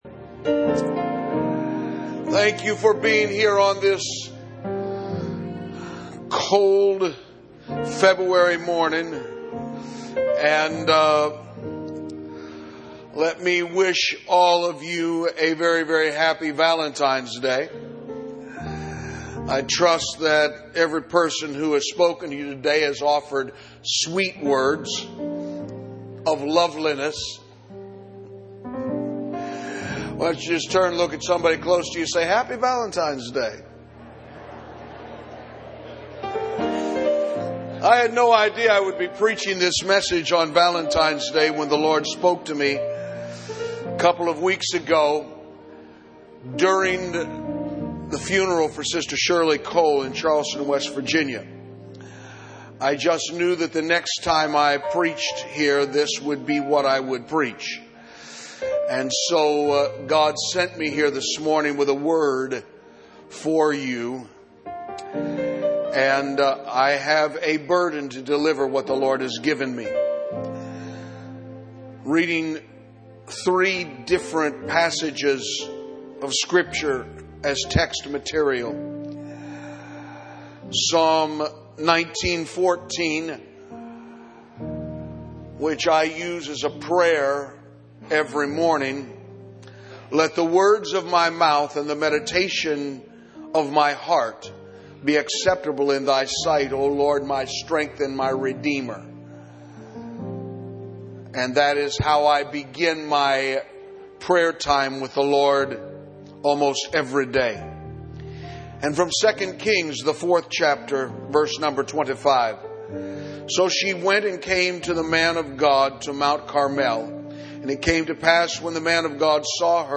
Event Archives - Page 30 of 65 - The Calvary Apostolic Church Sermon Archive